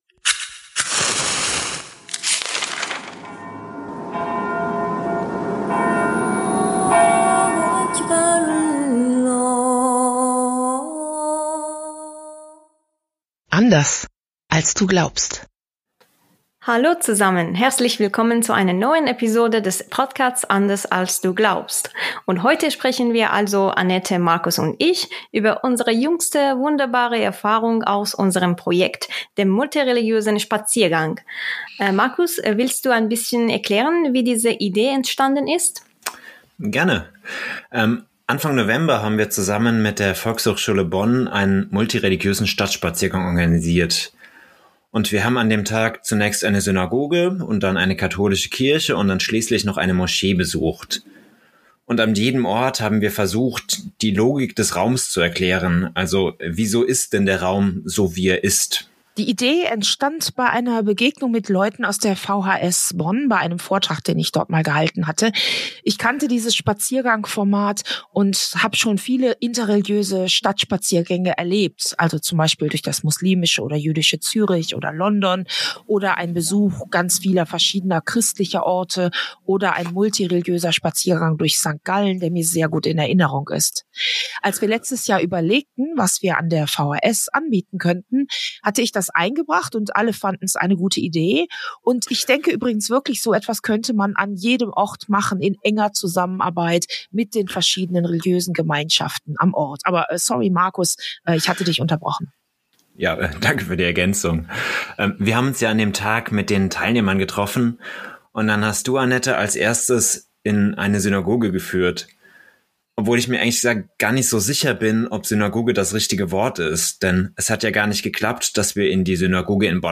Gespräch über einen multireligiösen Stadtspaziergang
Ein jüdisch-christlich-muslimisches Gespräch